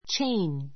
tʃéin チェ イン